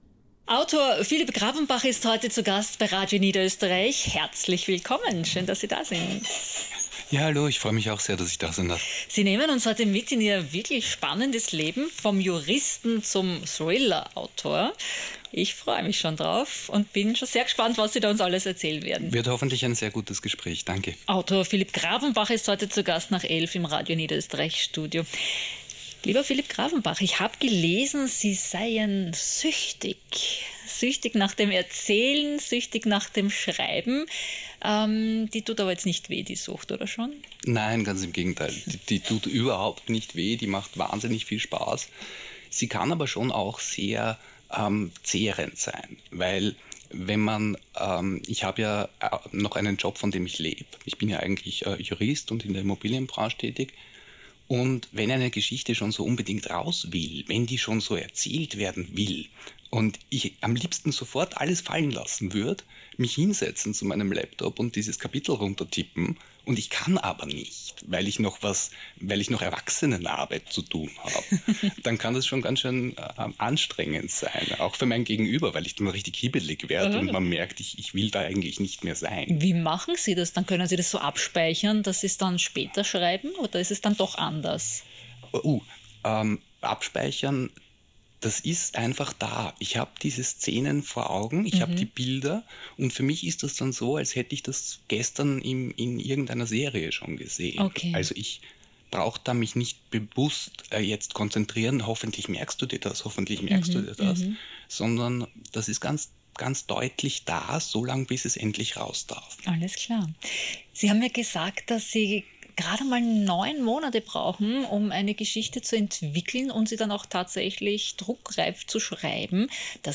RADIO NIEDERÖSTERREICH – Interview